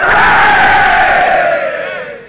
Amiga 8-bit Sampled Voice
NOISE.mp3